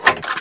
LEVER1.mp3